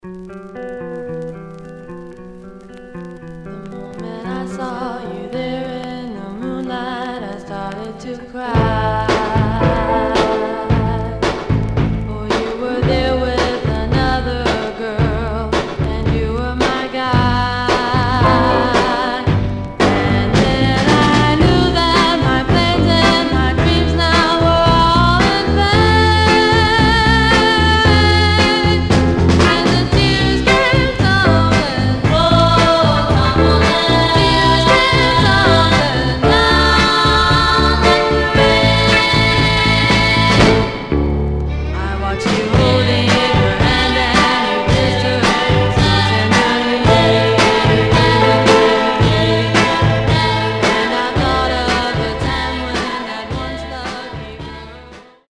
Stereo/mono Mono
Black Female Group